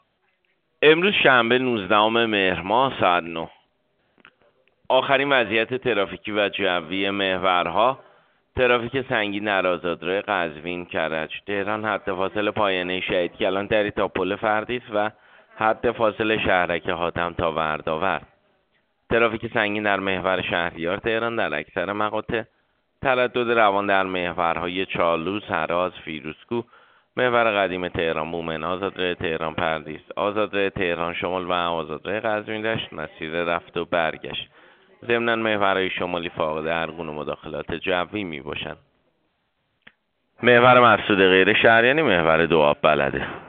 گزارش رادیو اینترنتی از آخرین وضعیت ترافیکی جاده‌ها ساعت ۹ نوزدهم مهر؛